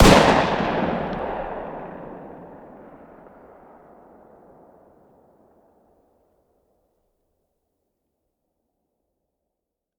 fire-dist-10x25-pist..>2024-09-10 22:10 507K